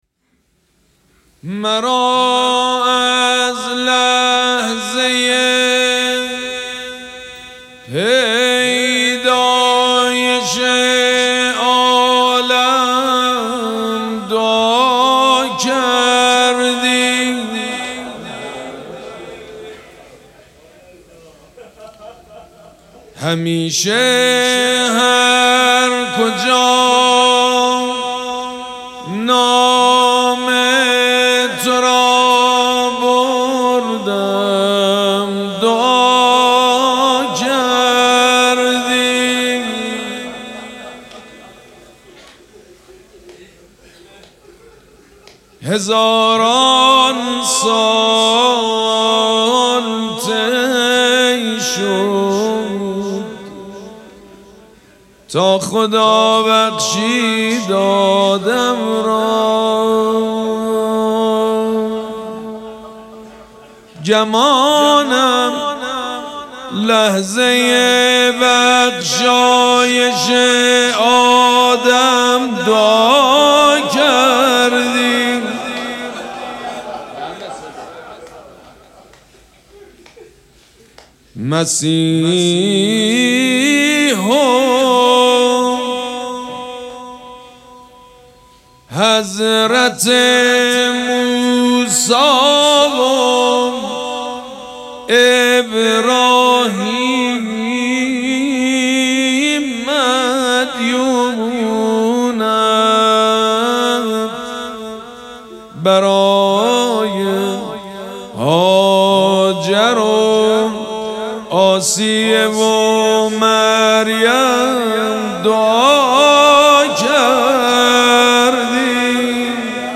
شب اول مراسم عزاداری دهه دوم فاطمیه ۱۴۴۶
حسینیه ریحانه الحسین سلام الله علیها
مدح
مداح
حاج سید مجید بنی فاطمه